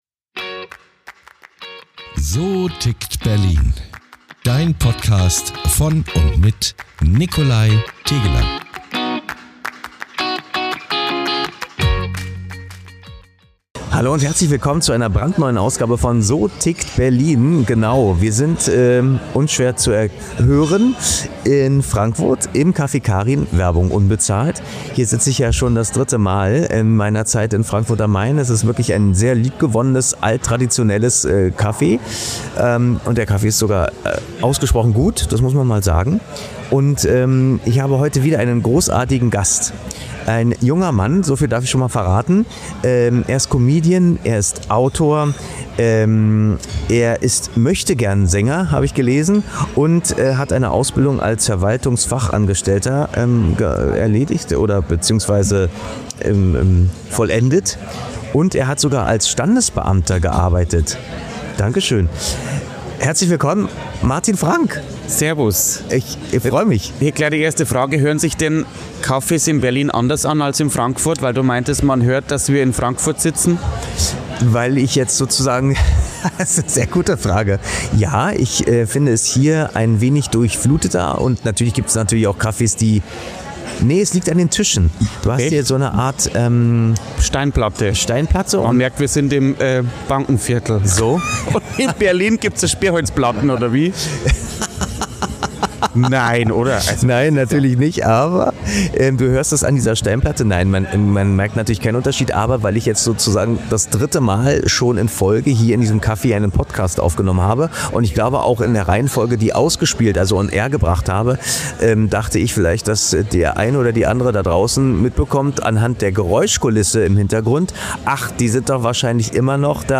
Ein ehrliches, pointiertes und sehr unterhaltsames Gespräch über Identität, Herkunft, Selbstironie – und die Frage, warum „anders sein“ vielleicht genau die größte Stärke ist.